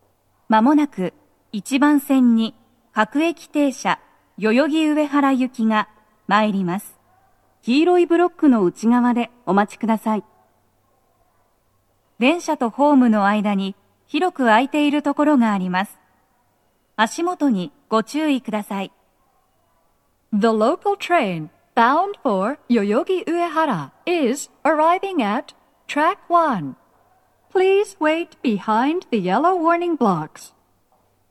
スピーカー種類 BOSE天井
鳴動は、やや遅めです。
女声
接近放送1